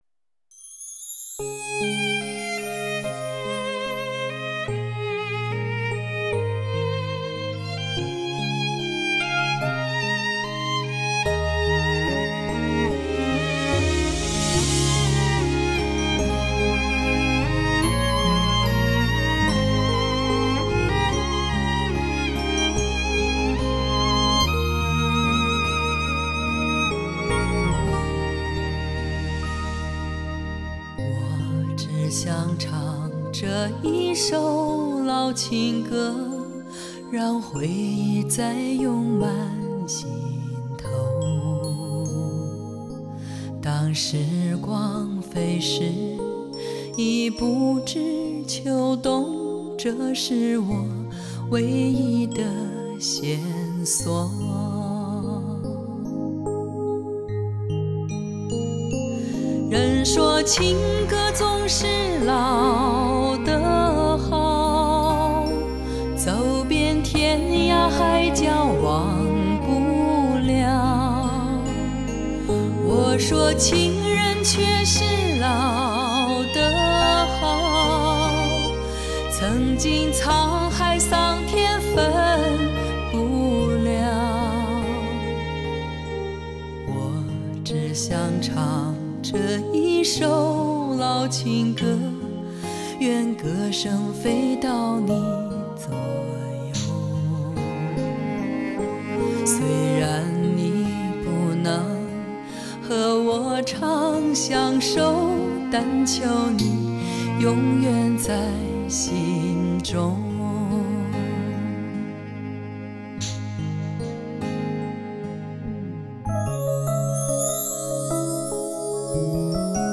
多声道音频技术的应用，
采用多声道分轨录音，对于声音的采集完全做到每一个乐器，
即使是一件很小的打击乐器都单独使用一个麦克风和音轨进行收录，